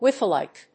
アクセント・音節wífe・lìke